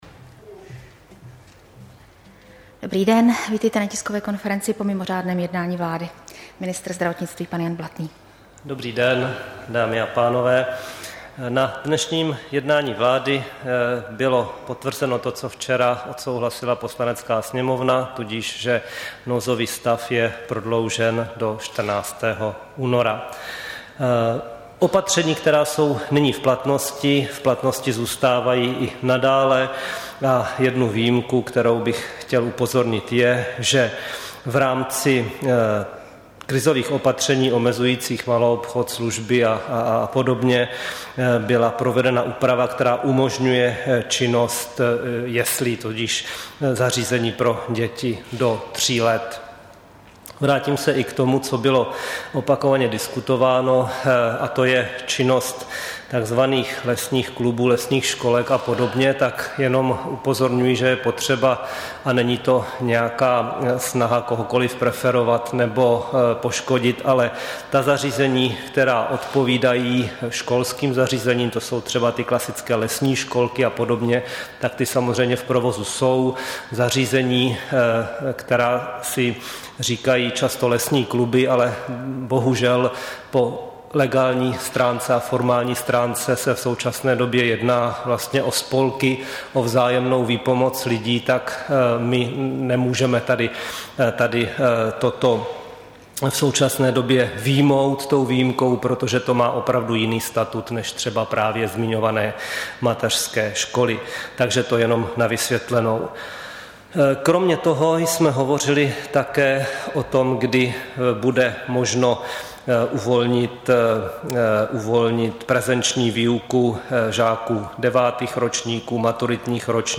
Tisková konference po mimořádném jednání vlády 22. ledna 2021